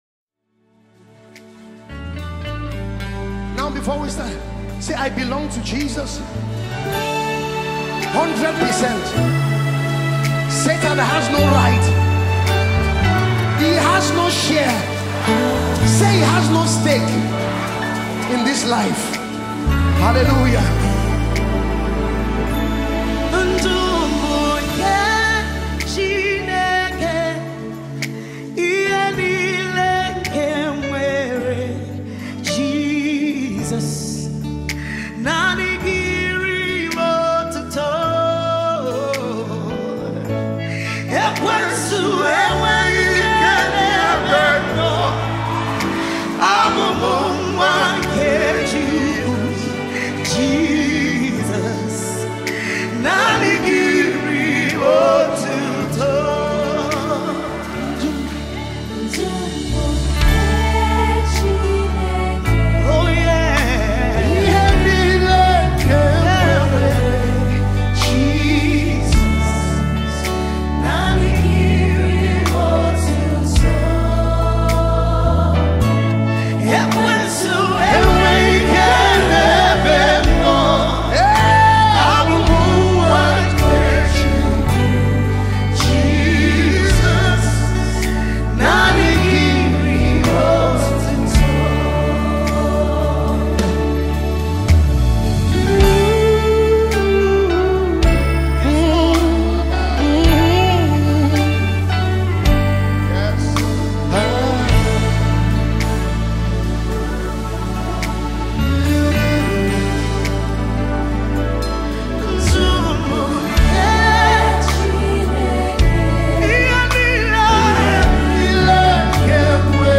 Gospel
It’s a powerful song of affirmation.